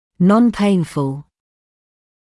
[ˌnɔn’peɪnfl][ˌнон’пэйнфл]безболезненный